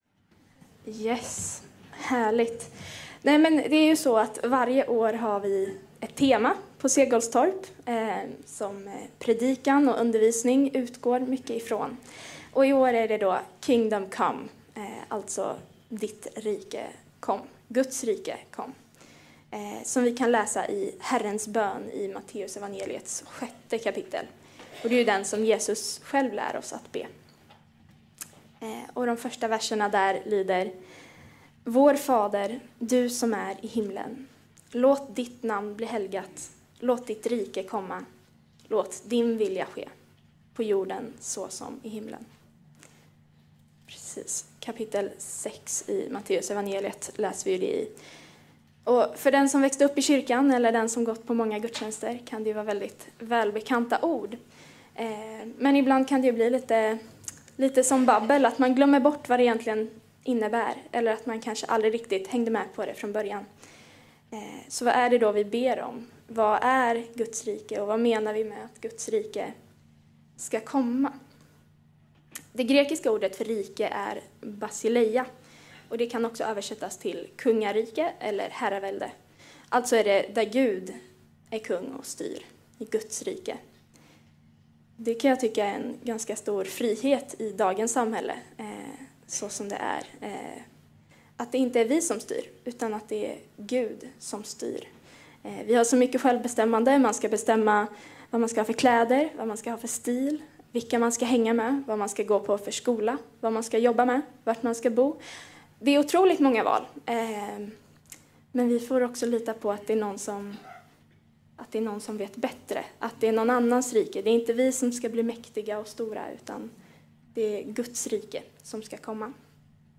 Segolstorpgudstjänst den 11 maj 10:30